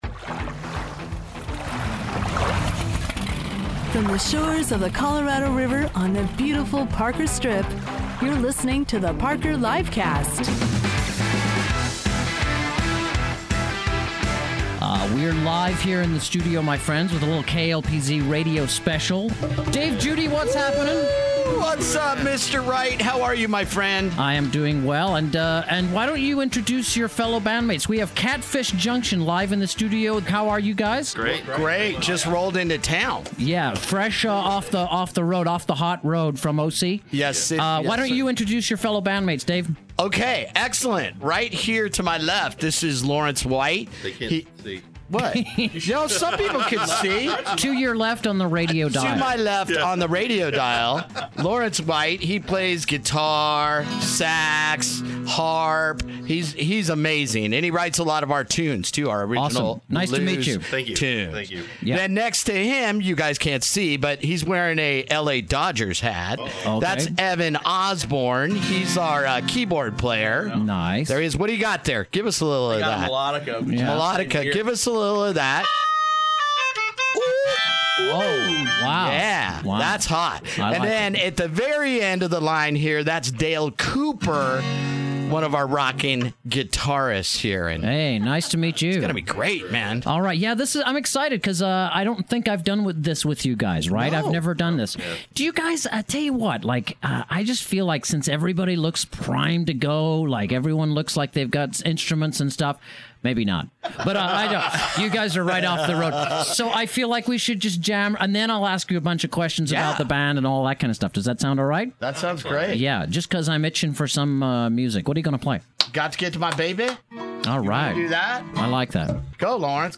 Blues
performed live acoustically in the studio